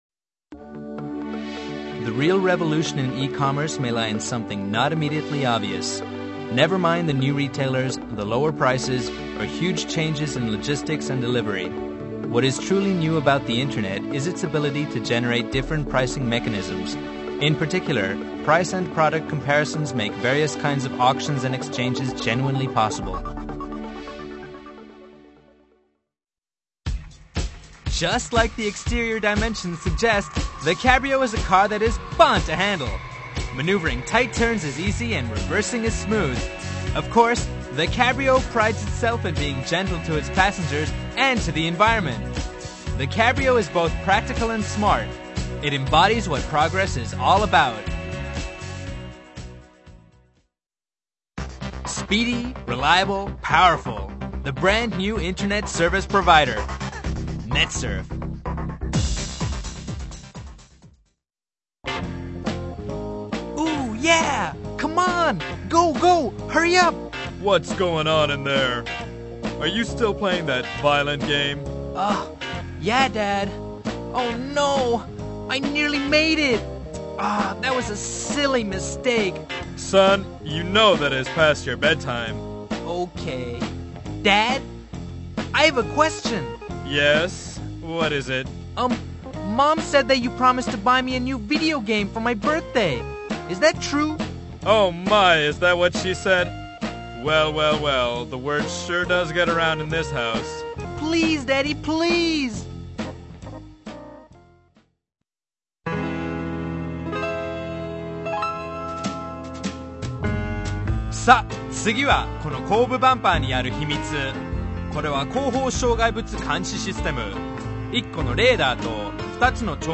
音声サンプル